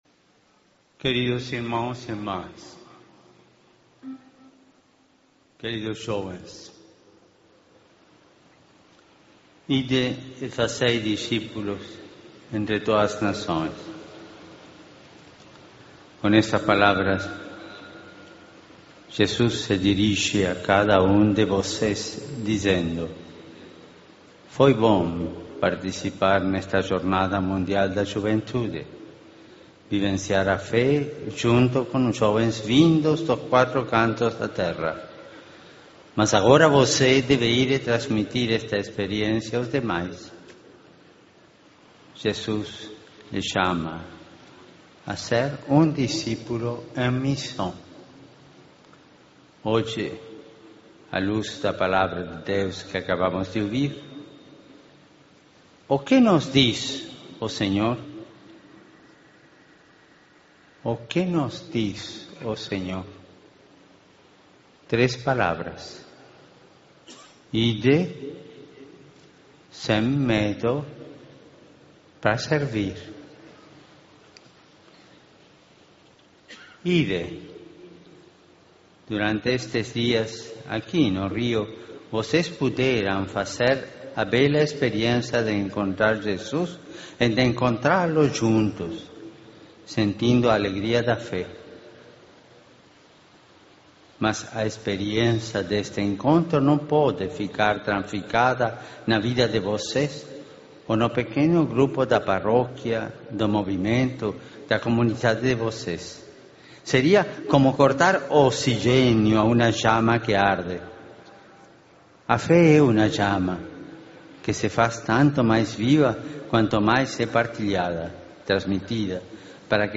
Homilía del Papa Francisco en la Misa de Clausura de la JMJ